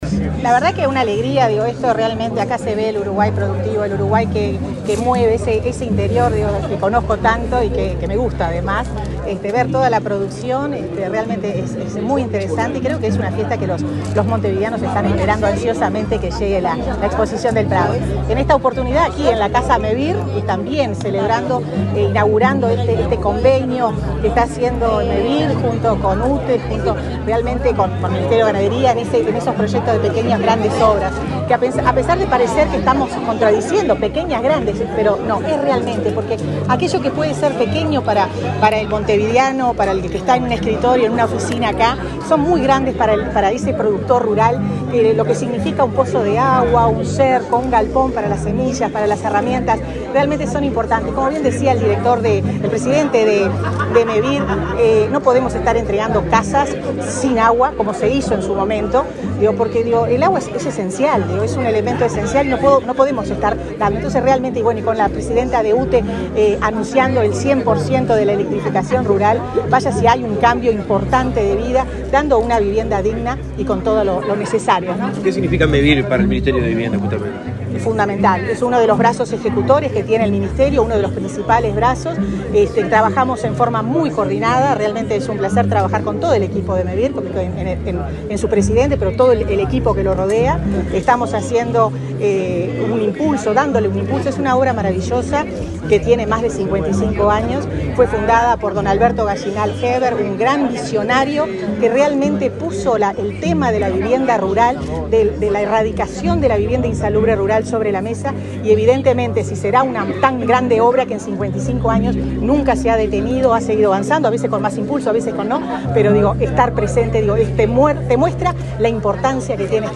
Declaraciones de la ministra de Vivienda, Irene Moreira
Luego, dialogó con la prensa.